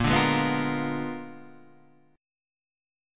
A#7b9 chord {6 5 6 4 6 4} chord